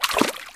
Footsteps_Water_jump.ogg